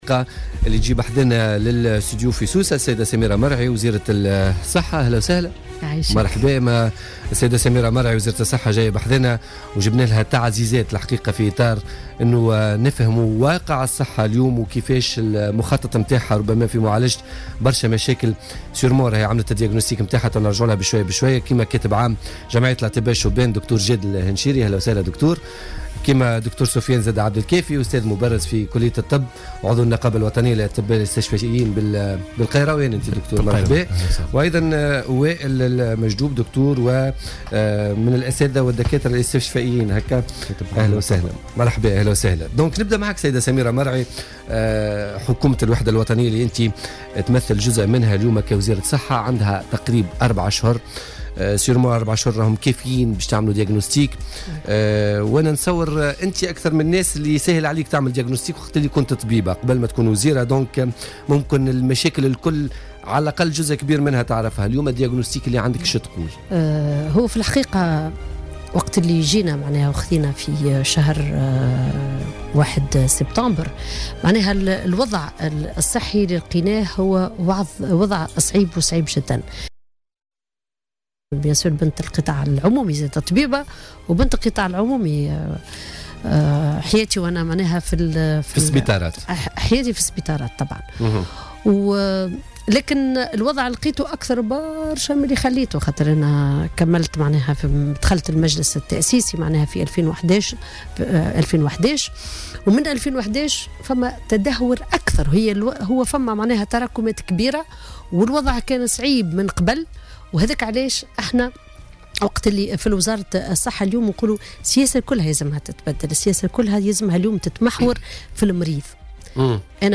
ونبّهت ضيفة "بوليتيكا" إلى الصعوبات التي يمرّ بها القطاع خاصة على مستوى الصحة العمومية مؤكدة على ضرورة اصلاح منظومة هذا القطاع.